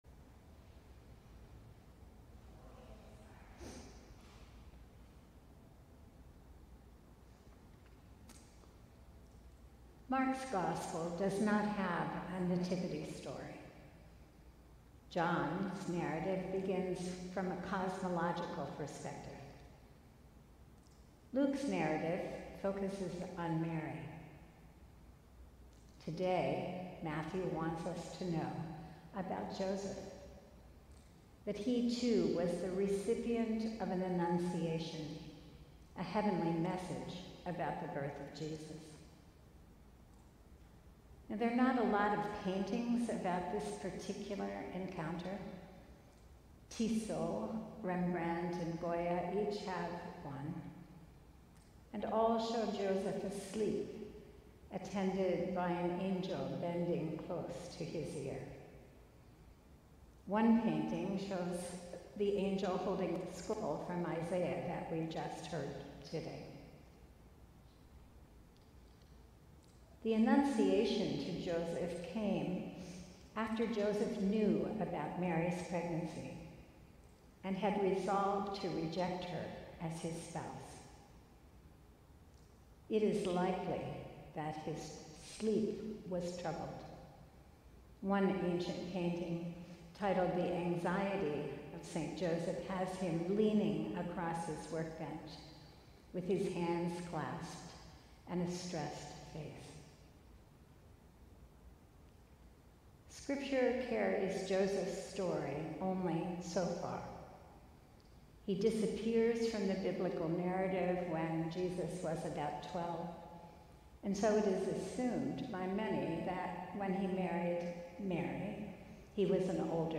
Sermon: Joseph's Short Story